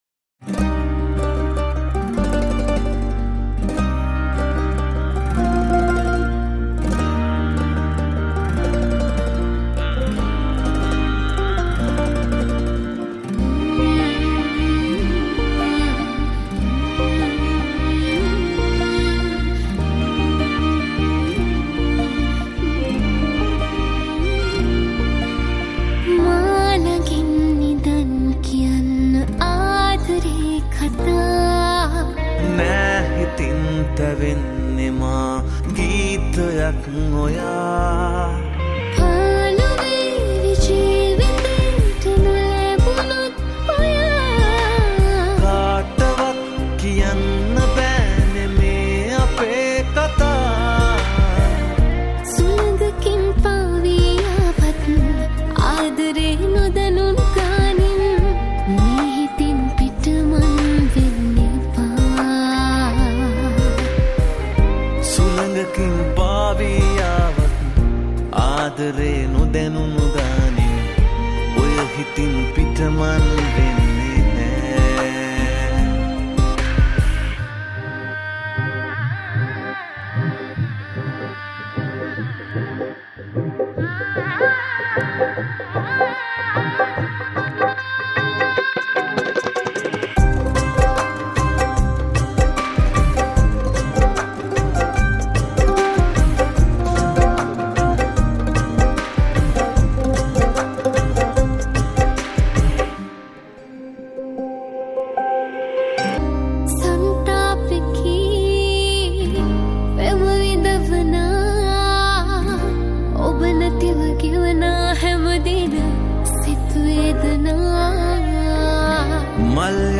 Teledrama Song